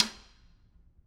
Percussion
Snare2-taps_v3_rr1_Sum.wav